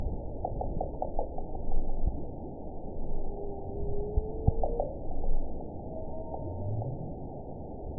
event 921887 date 12/20/24 time 10:14:04 GMT (11 months, 2 weeks ago) score 9.11 location TSS-AB03 detected by nrw target species NRW annotations +NRW Spectrogram: Frequency (kHz) vs. Time (s) audio not available .wav